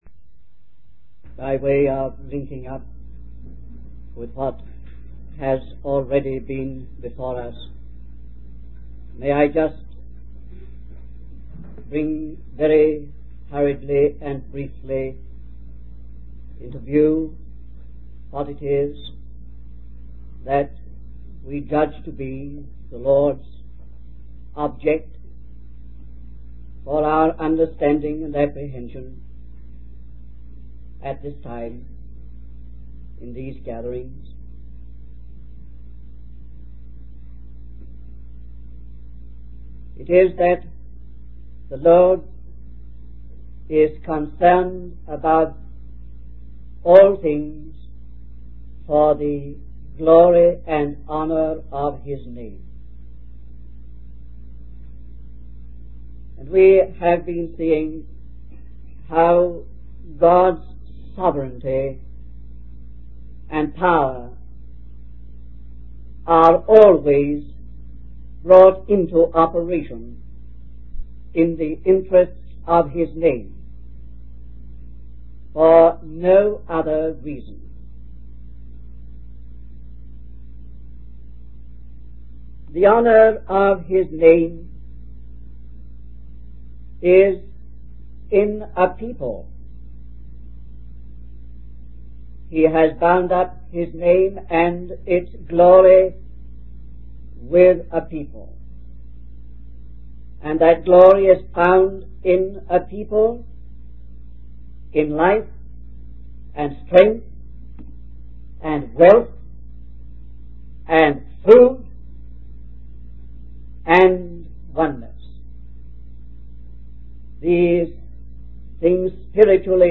In this sermon, the preacher discusses the story of Gideon from the book of Judges and relates it to the letter to the Philippians. The preacher emphasizes that God calls specific instruments according to His purpose and for the glory of His name. The preacher also highlights the importance of having the mind of Christ and emptying oneself to be used by God.